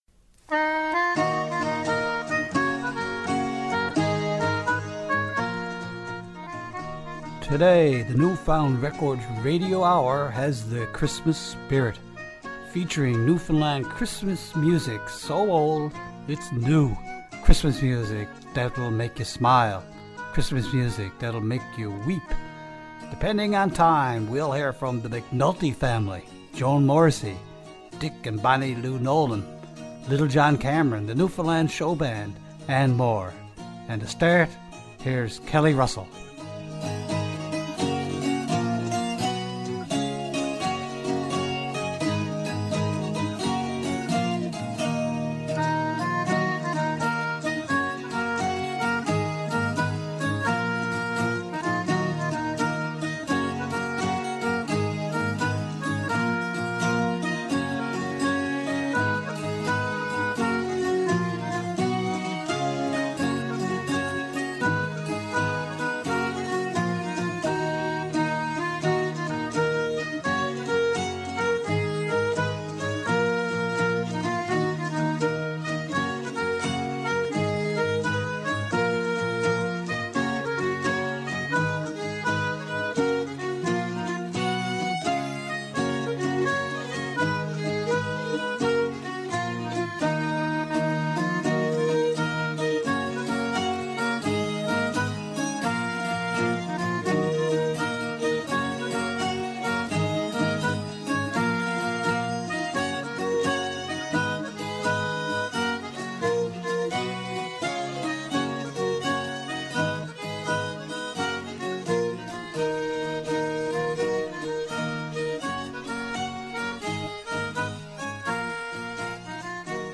Vinyl records by Newfoundland & Labrador's pioneer recording artists.